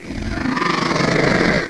thunderCannonGrowl.wav